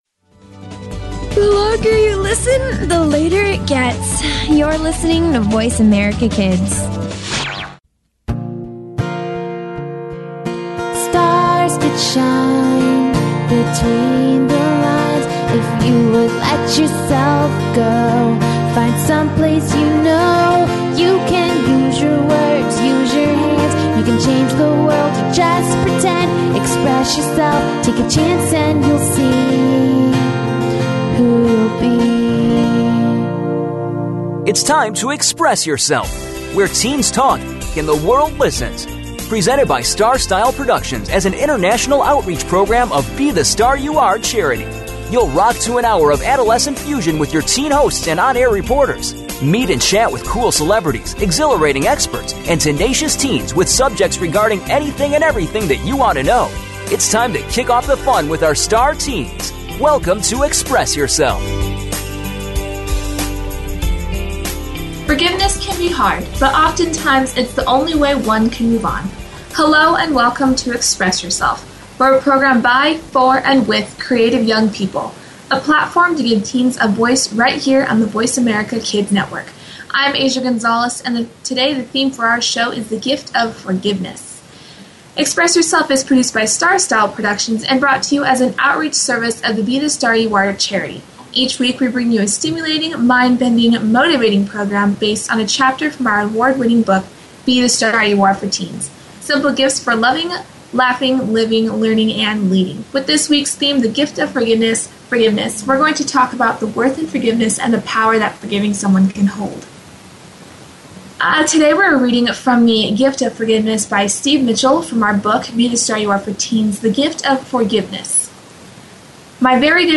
Finally, our teen hosts discuss how forgiveness has moved them forward in their own lives and how teens can embrace this virtue.